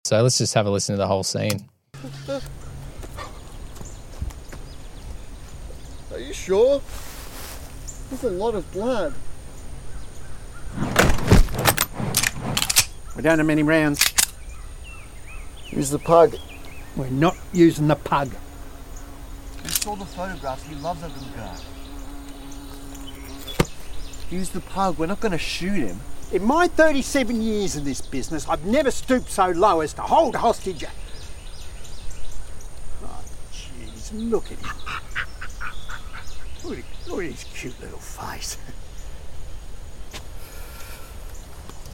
Sound Design tutorial.